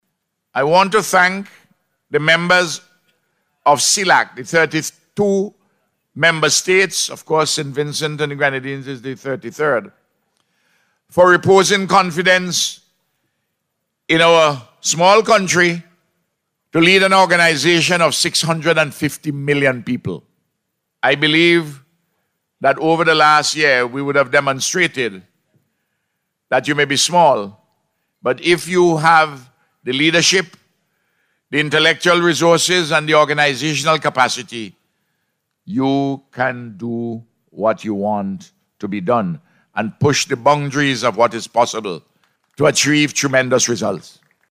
The Leaders and Diplomats from throughout the region were officially welcomed by Prime Minister Gonsalves at a Cocktail Reception last night, at Sandals Resort.